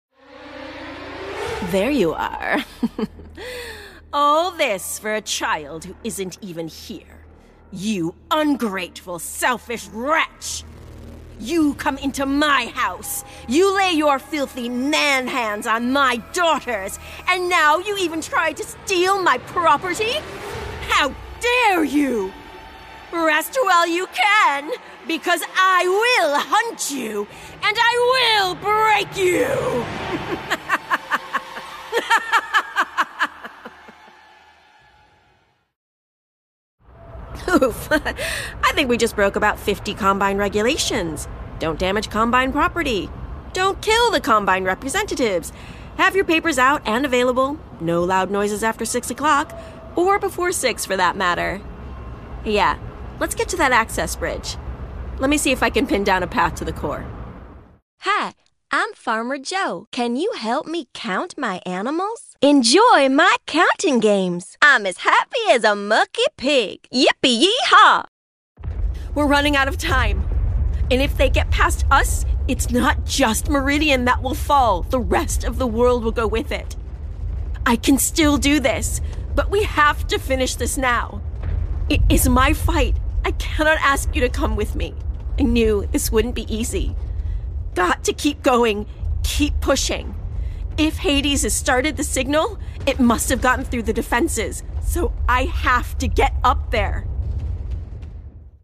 Video Game Reel
Playing age: Teens - 20s, 20 - 30sNative Accent: AmericanOther Accents: American, RP
• Native Accent: American-Midwest
Her refined accent skills, honed through work in LA, Ireland, and the UK, lend authenticity and depth to every performance, making her a unique, compelling and dynamic voice actor.